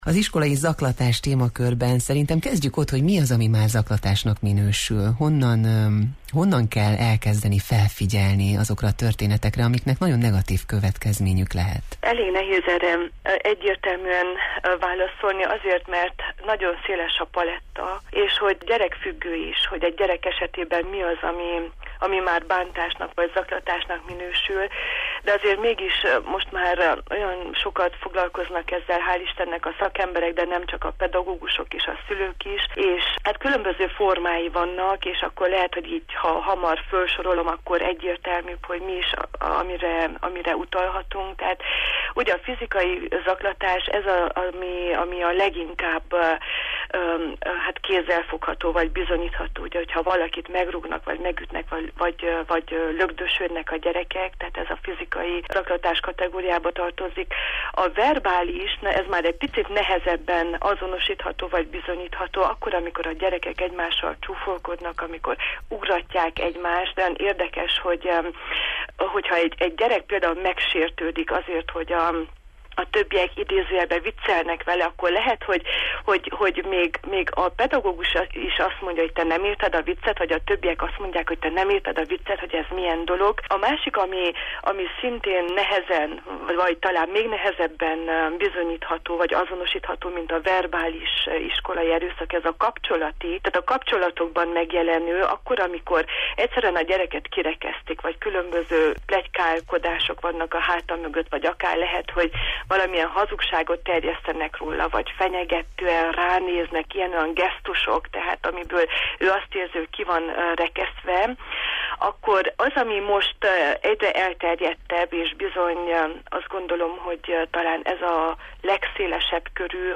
pszichológussal beszélgettünk a Jó reggelt, Erdély!-ben: